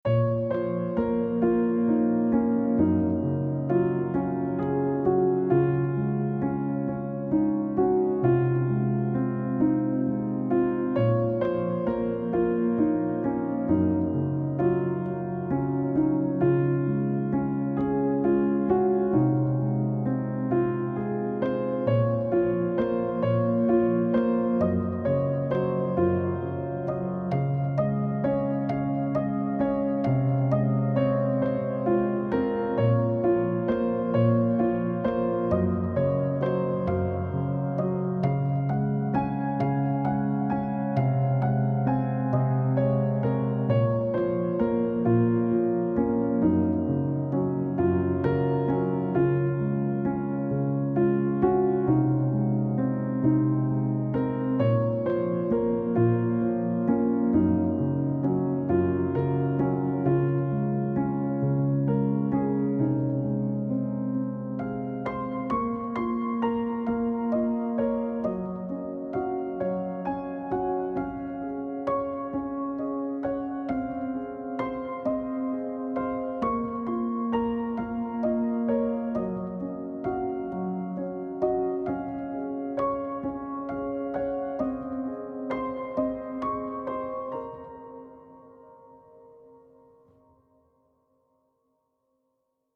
پیانو
آرامش بخش پیانو عاشقانه عصر جدید موسیقی بی کلام